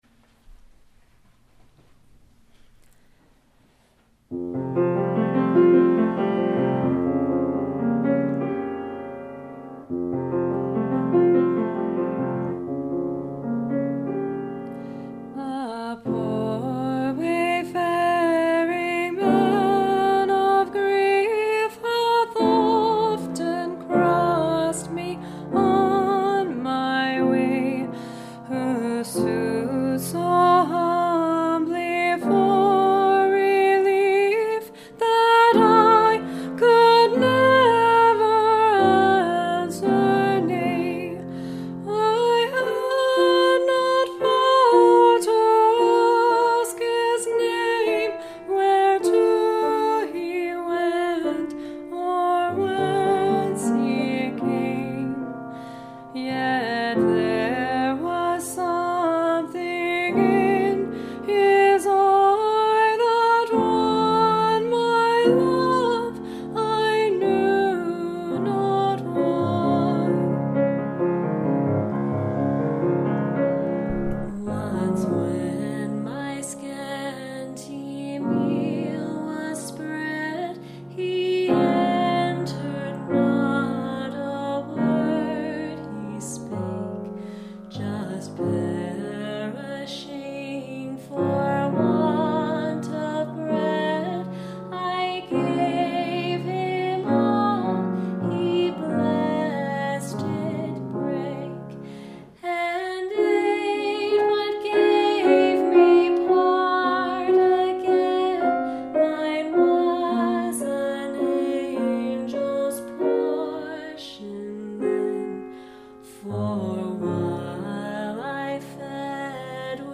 Duet, SA